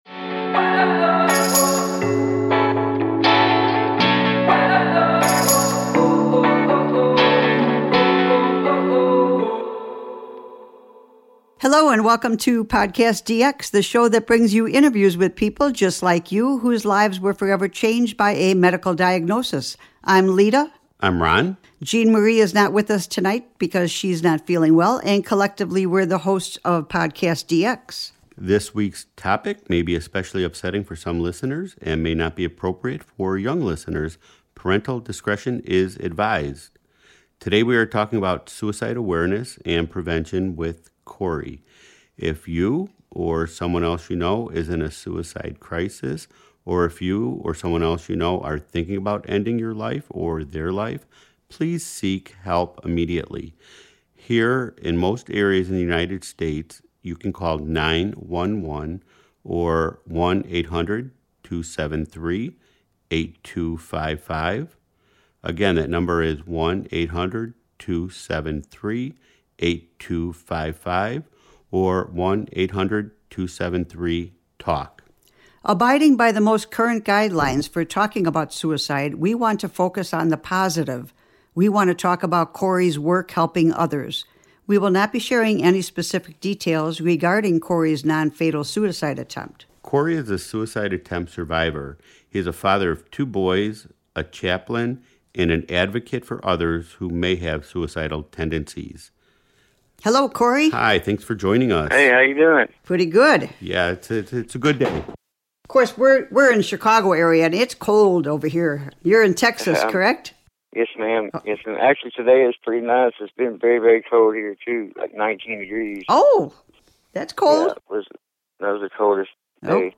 Our guest today is a suicide survivor and speaks openly about this difficult topic.